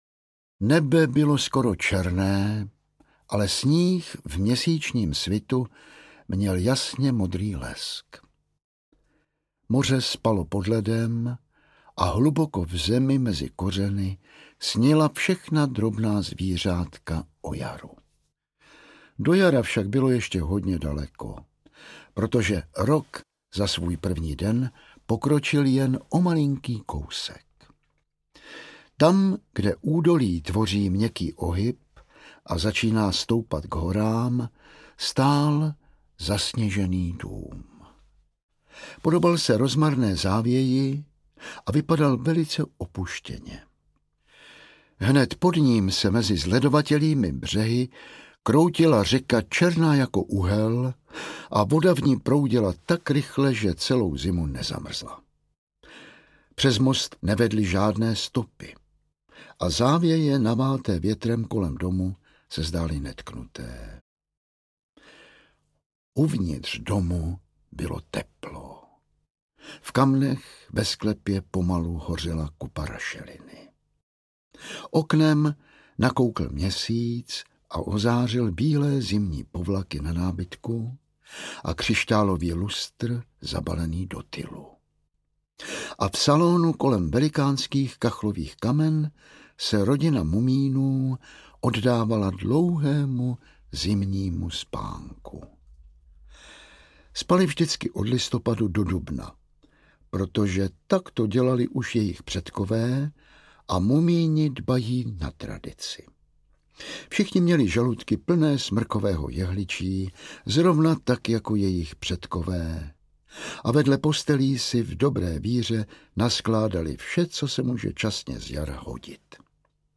Čarovná zima audiokniha
Ukázka z knihy